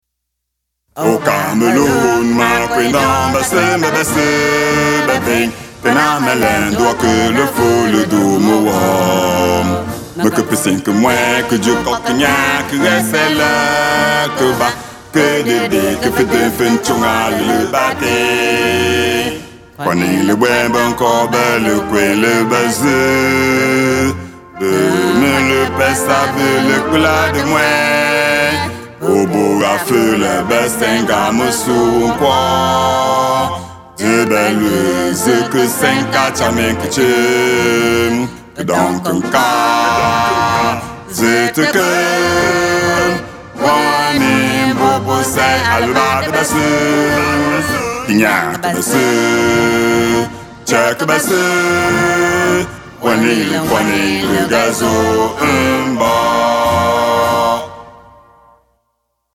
L'hymne national lɨfαʼ a été traduit par les membres du Codelefa au cours de l'année 2022, corrigé et chanté pour la première fois lors de la fête du 20 Mai 2023 à Deuk. La version que vous écoutez présentement est un enrégistrement dans un studio à Bafia le 27 février 2024.